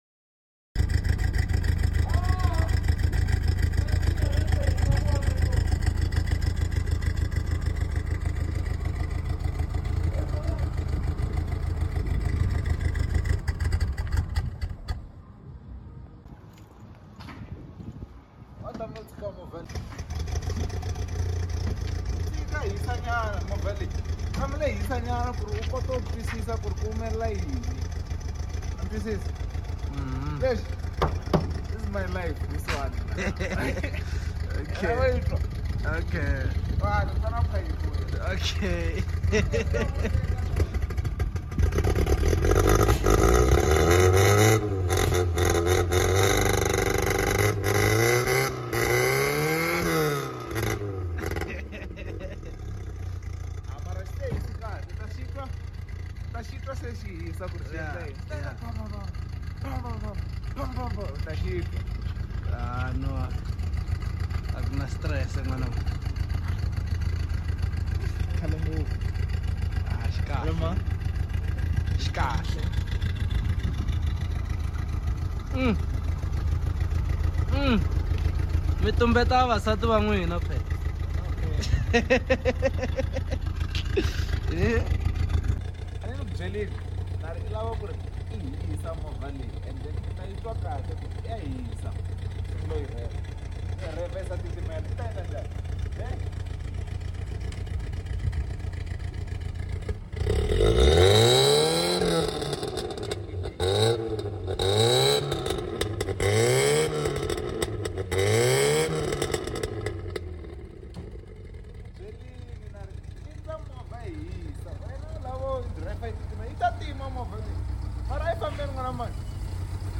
MK1 Carburretor pipe